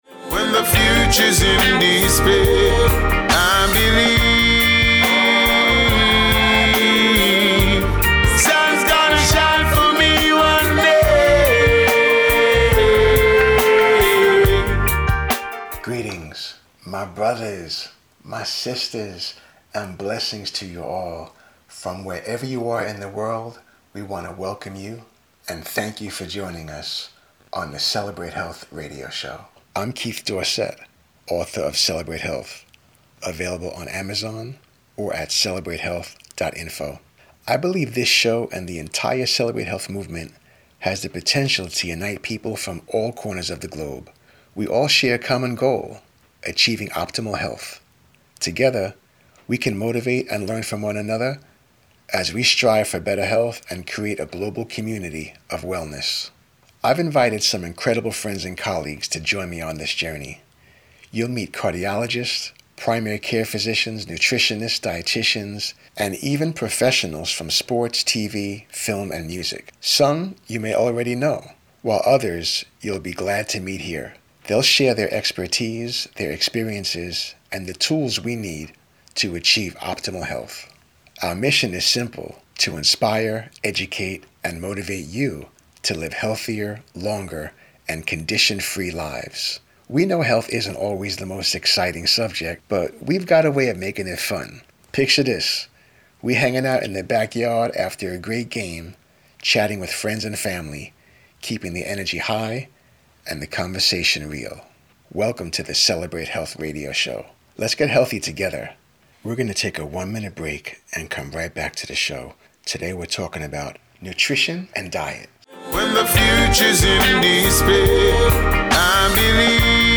Radio content was developed that is based on each chapter of the book. Although it runs alongside the book, there is witty and gritty commentary embedded within.